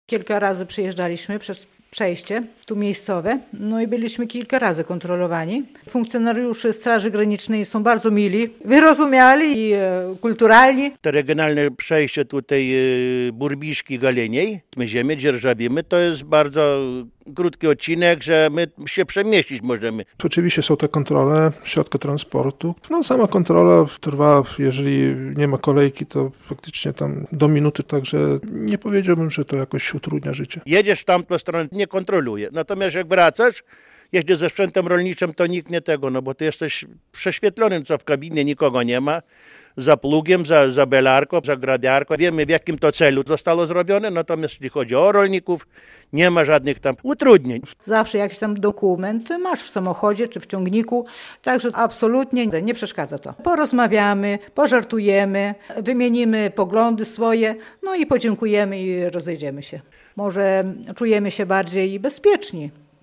Kontrola graniczna rolników - relacja